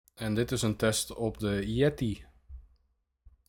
Hieronder heb ik een drietal geluidsopnamen gemaakt met verschillende microfoons om de Quadcast 2 tegen af te meten.
Als proef op de som heb ik tijdens een meeting de mening van collega’s gevraagd over de Quadcast 2 en de Blue Yeti X. Al mijn collega’s gaven aan dat de Quadcast 2 een veel voller en veel prettiger geluid geeft, wat mij erg verbaasde, maar zeker een pluspunt is tegenover de Yeti X. Het geluid van de Yeti is iets kouder en vooral de diepere tonen komen beter door via de Quadcast 2.
Blue Yeti X
De Blue Yeti X is ook iets gevoeliger voor omgevingsgeluid en zal deze daarom sneller oppikken, zoals bijvoorbeeld computervans of toetsenbordgeluiden.
Sample-microfoon-yetix.m4a